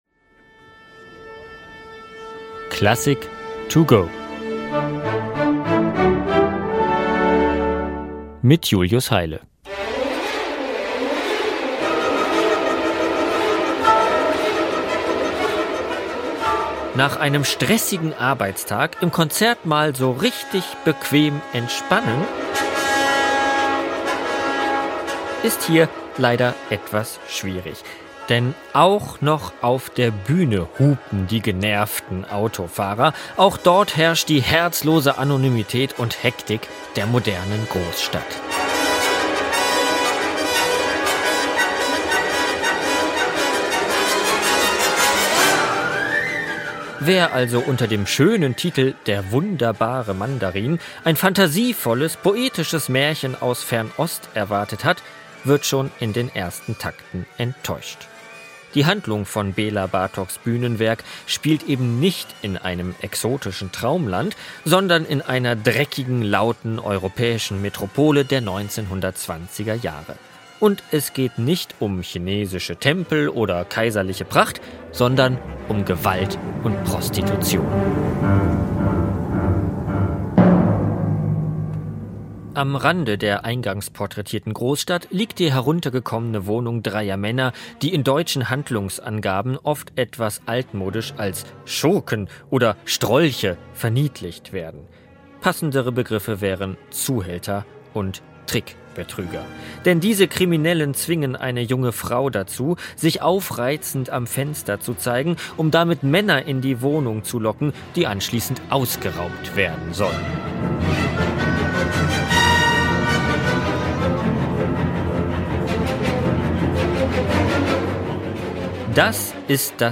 in seiner Kurzeinführung für unterwegs.